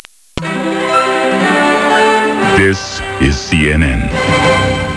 Click on the Coach to hear his words of inspiration for the American Team!
jamesearljones.wav